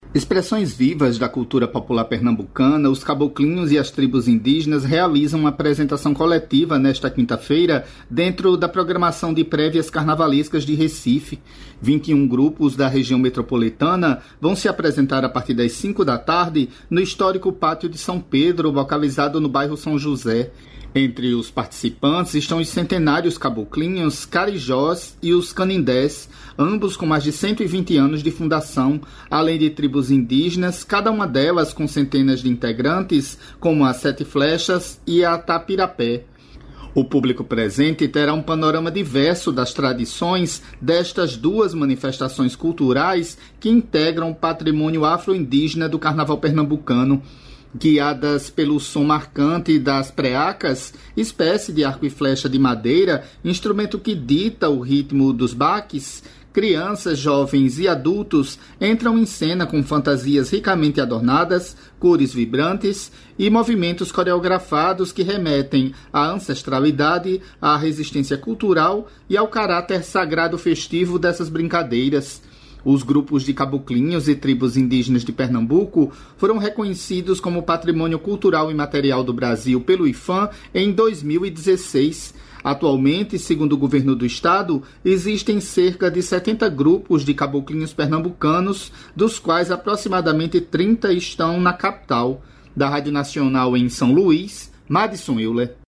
Repórter da Rádio Nacional